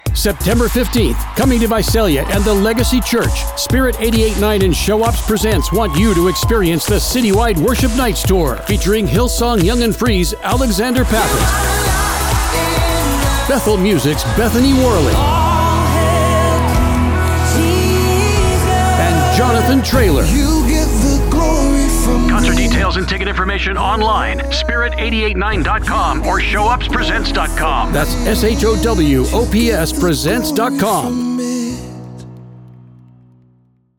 Inspire and uplift your audience with a sincere, heartfelt voice that aligns with the message of contemporary Christian music.
Concert Promos
Contemporary Christian
ShowOps_CityWide_Visalia_Applause_mixdown.mp3